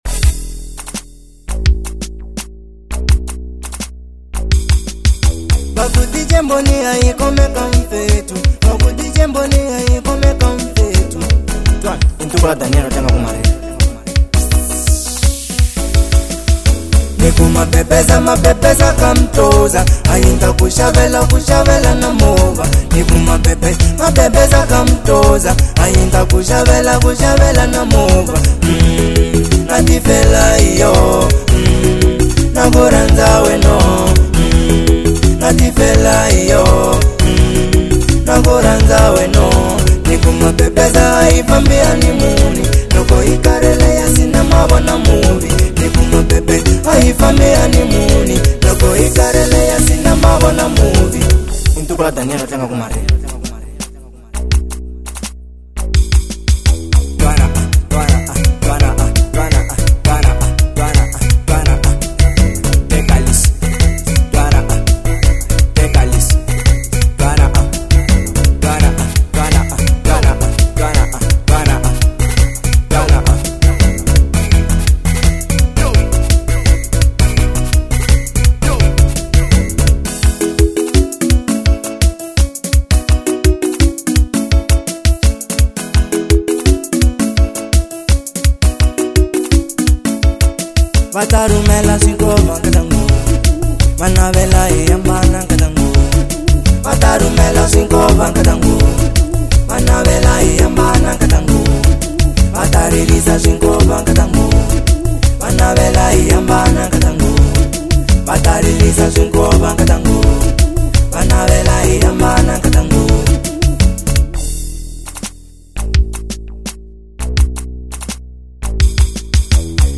05:05 Genre : Xitsonga Size